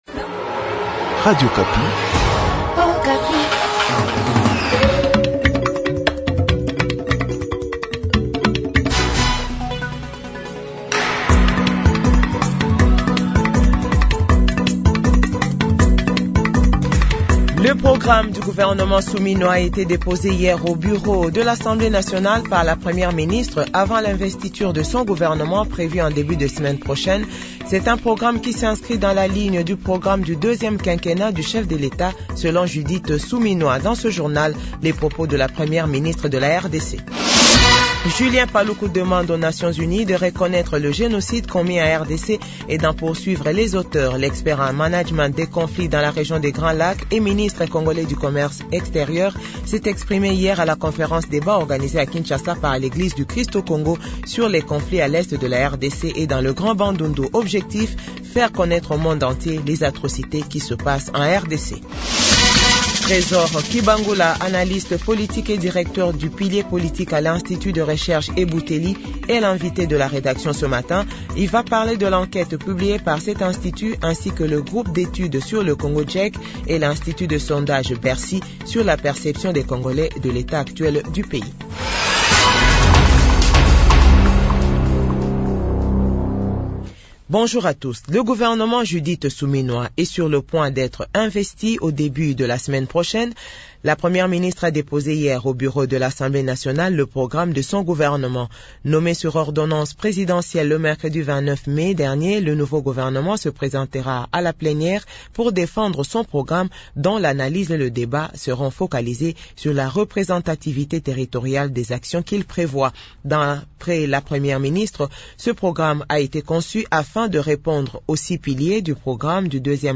JOURNAL FRANÇAIS 7H00 -8H00